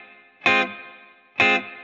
DD_TeleChop_130-Fmin.wav